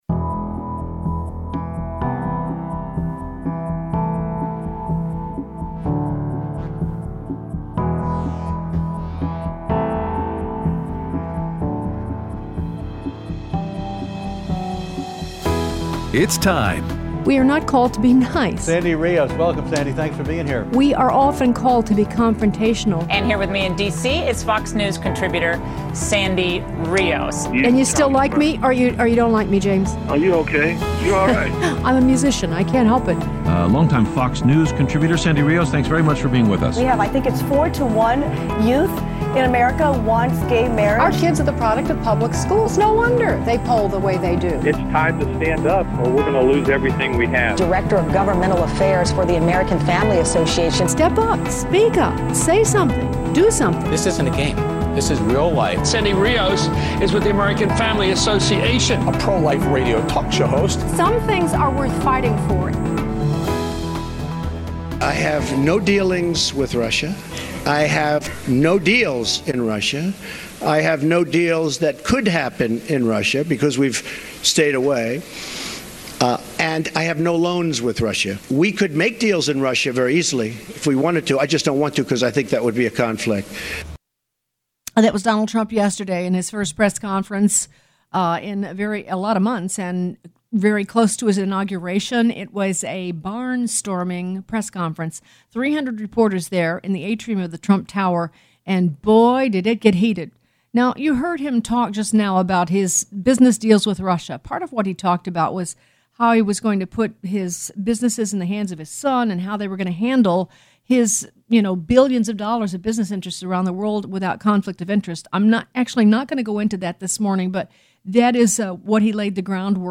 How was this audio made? Aired Thursday 1/12/17 on AFR 7:05AM - 8:00AM CST